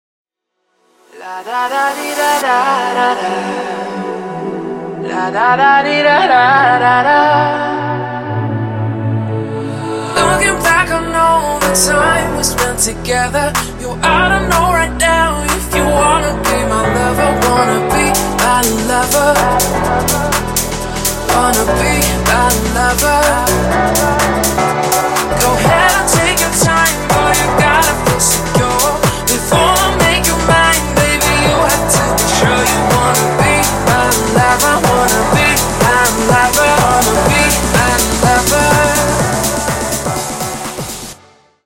женский вокал
remix
retromix
Club House
клавишные
future house